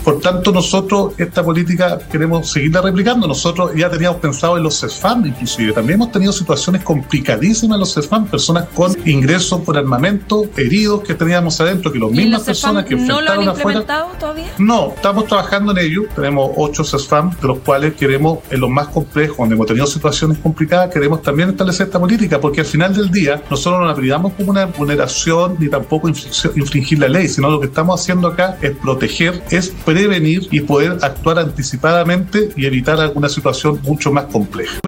En el diálogo, el alcalde, además, se defendió de las críticas por la instalación del detector de metales en el liceo, dando cuenta de que a nadie le genera ruido cuando los niños pasan por estas herramientas en diversas situaciones, como en los aeropuertos o en Tribunales de Justicia.